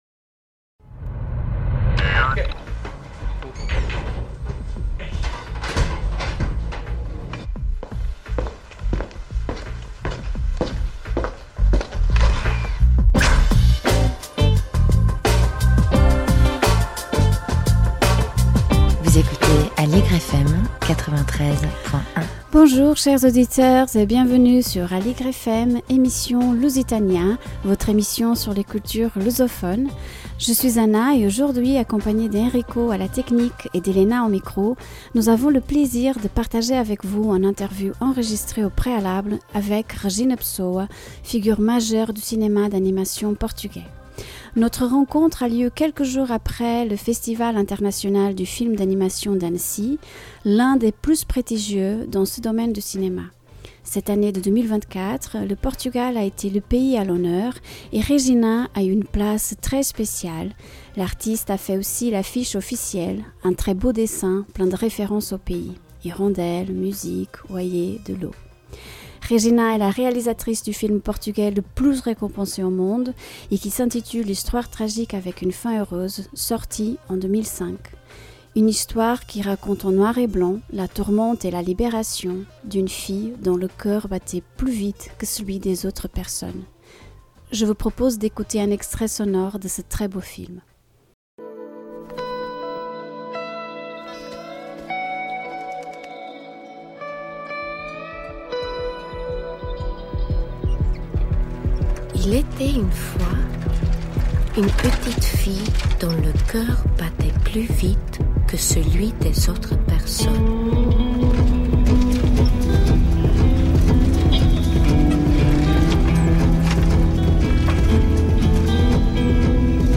la réalisatrice Regina Pessoa, figure majeure du cinéma d’animation portugais et autrice du film portugais le plus récompensé au monde